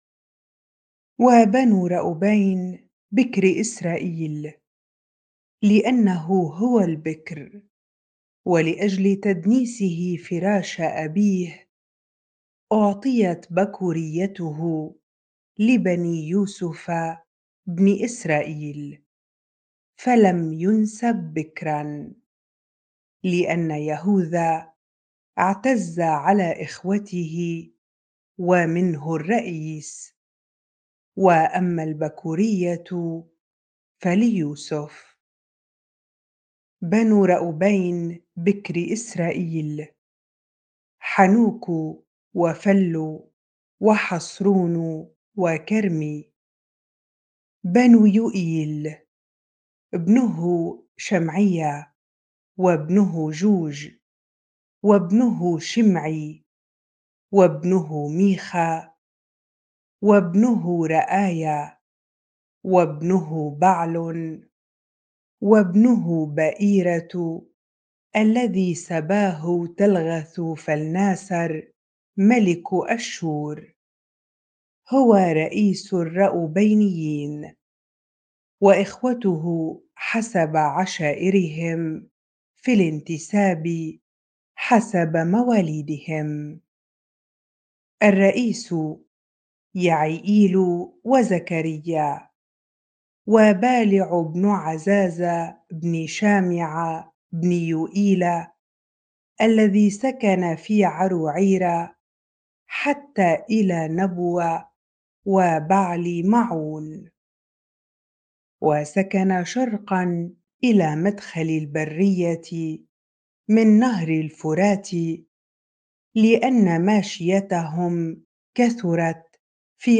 bible-reading-1 Chronicles 5 ar